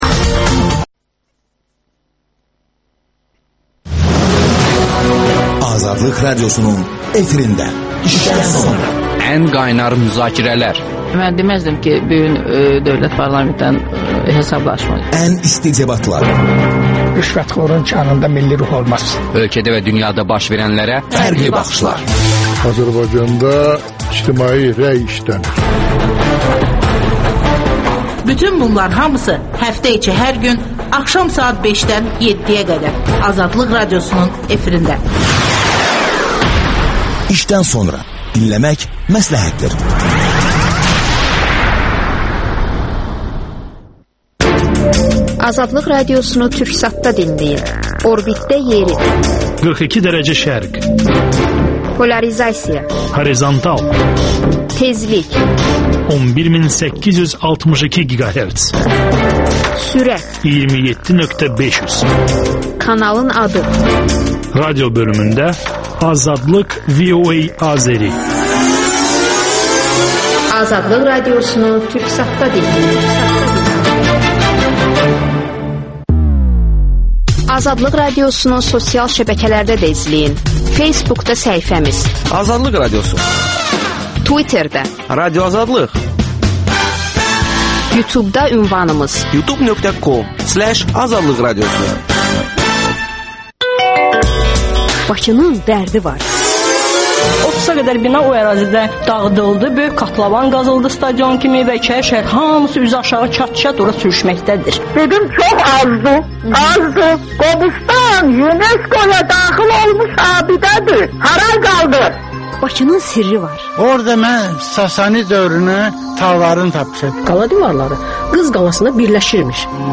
İşdən sonra - TQDK rəsmisi canlı yayımda...